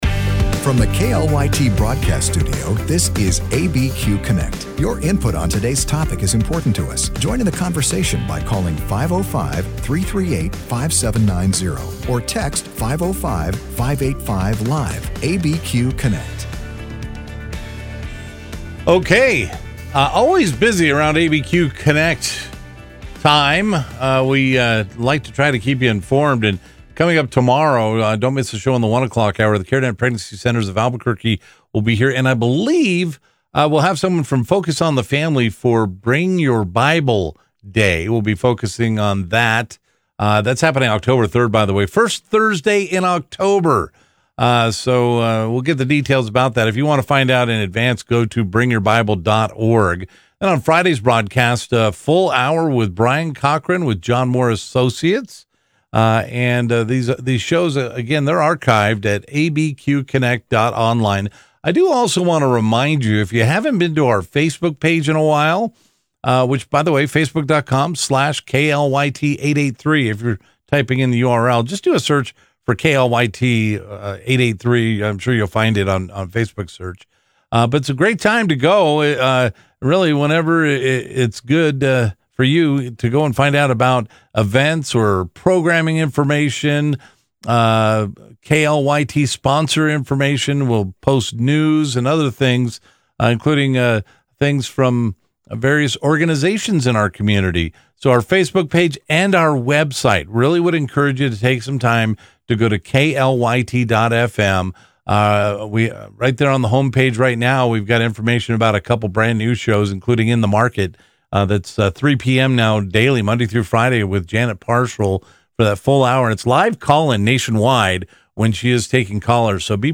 Live, local and focused on issues that affect those in the New Mexico area.